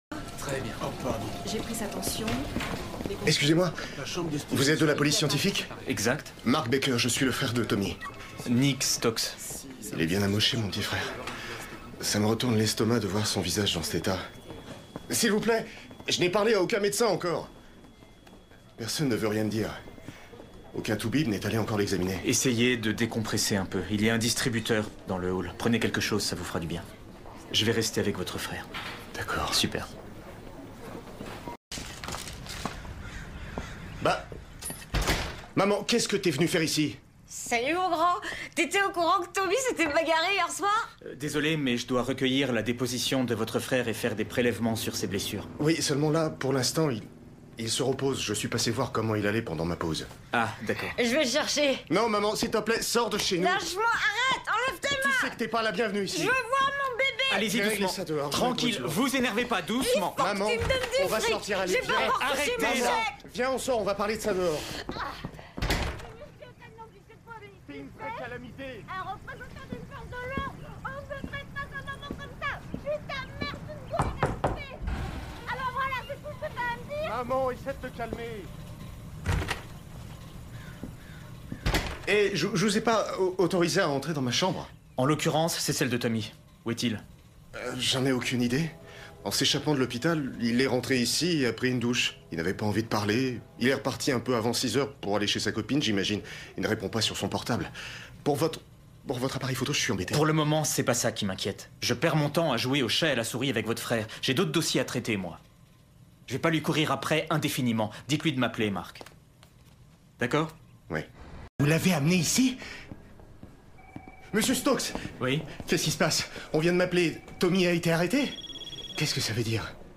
Ma hauteur de voix médium grave m’a permis de mettre en valeur ce protagoniste, mais aussi de renforcer le drame ainsi que l’intensité de la situation. Entre sensibilité et manipulation, j’ai dû jongler avec les tons pour captiver l’audience et faire ressentir la tension inhérente à ce personnage.